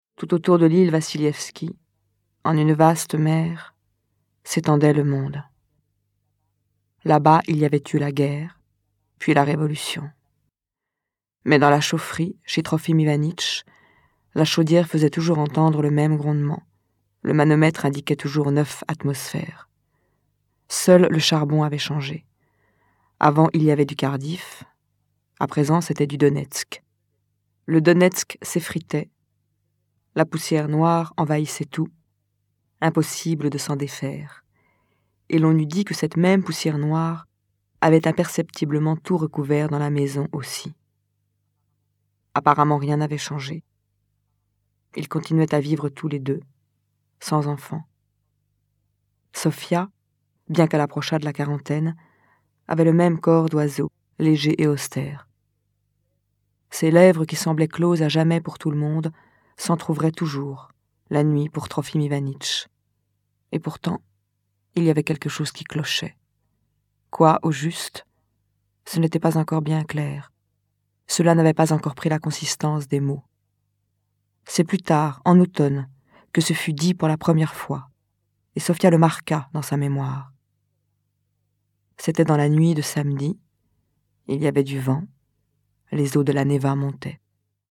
C’est l’occasion d’écouter les livres audio enregistrés dans la collection La Bibliothèque des voix des éditions des femmes-Antoinette Fouque.
Écoutez Isabelle Huppert vous faire la lecture des extraits :